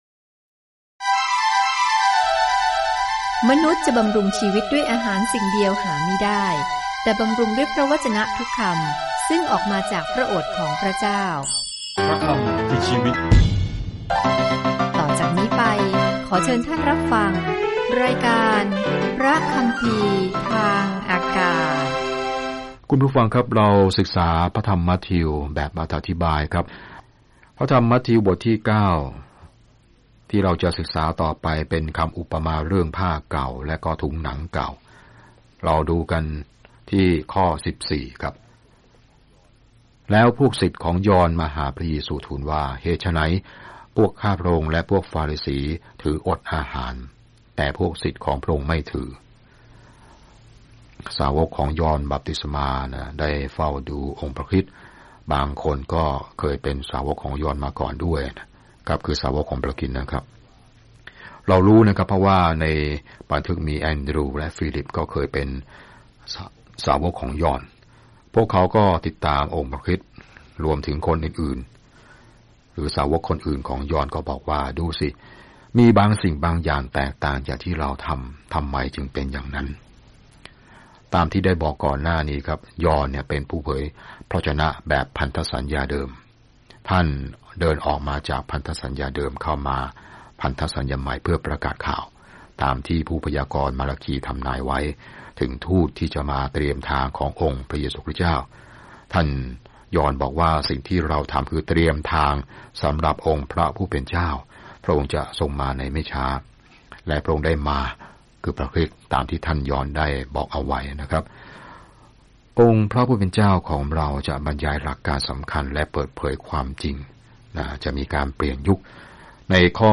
มัทธิวพิสูจน์ให้ผู้อ่านชาวยิวเห็นข่าวดีว่าพระเยซูคือพระเมสสิยาห์ของพวกเขาโดยแสดงให้เห็นว่าพระชนม์ชีพและพันธกิจของพระองค์ทำให้คำพยากรณ์ในพันธสัญญาเดิมเกิดสัมฤทธิผลอย่างไร เดินทางทุกวันผ่านมัทธิวในขณะที่คุณฟังการศึกษาด้วยเสียงและอ่านข้อที่เลือกจากพระวจนะของพระเจ้า